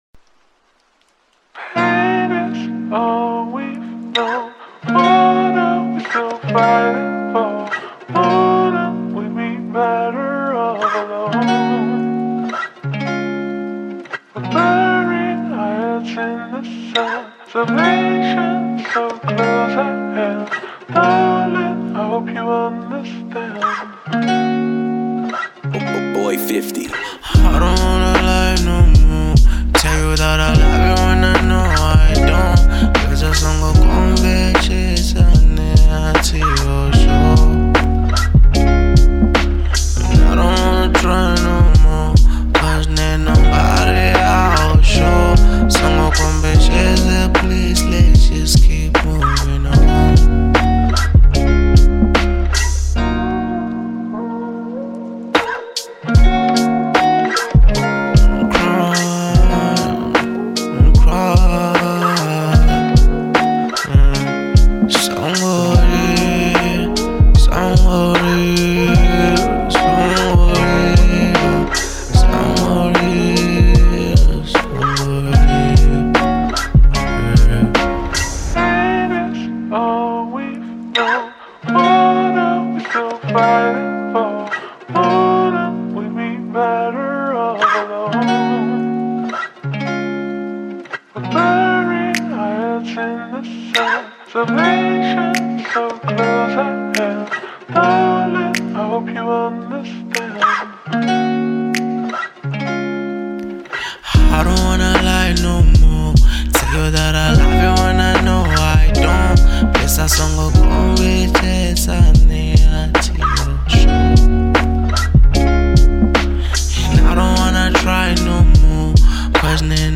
02:37 Genre : Venrap Size